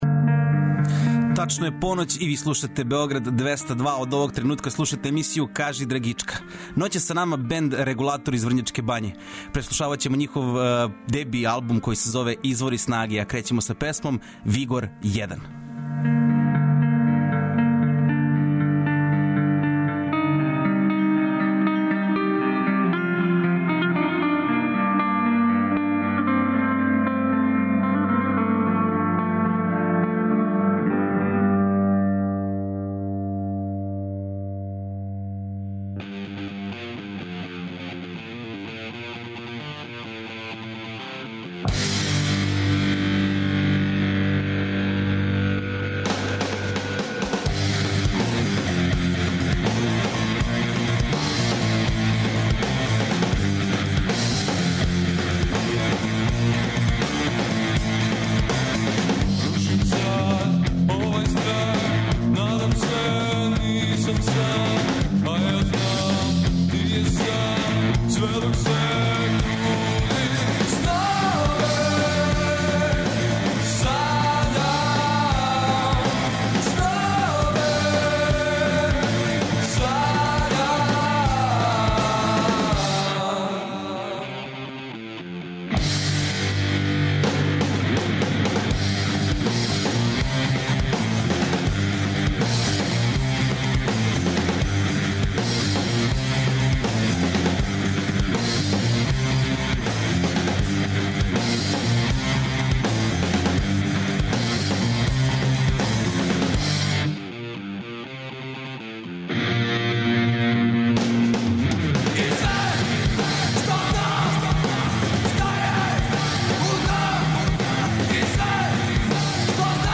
Свако вече, од поноћи на Двестадвојци у емисији Кажи драгичка гост изненађења!